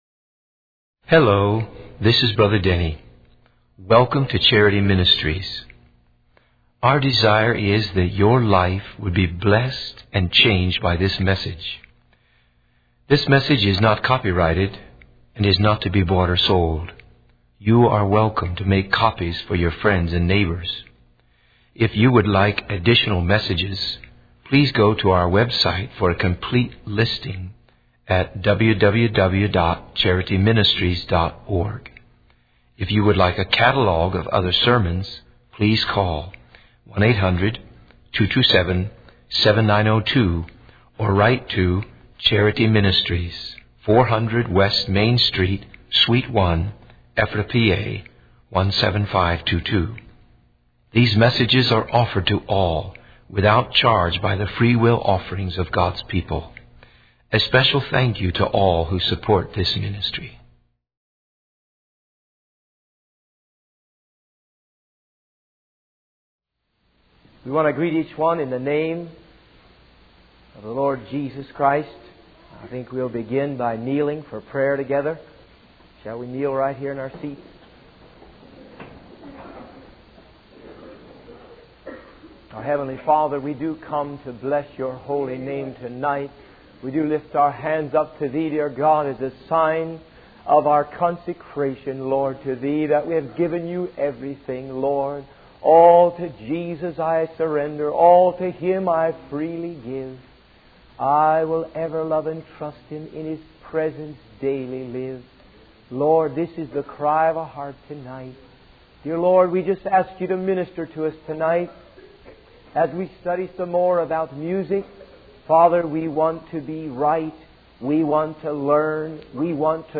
In this sermon, the speaker emphasizes the importance of discerning the spirit, message, fruit, and lives behind the music we listen to. They highlight the need for music to be rich in the truths of God's word, rather than repetitive or emotionally-driven.